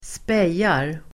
Ladda ner uttalet
Uttal: [²sp'ej:ar]